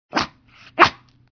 На этой странице собраны разнообразные звуки, связанные с померанскими шпицами: от звонкого лая щенков до довольного поскуливания.
Звуки шпица дважды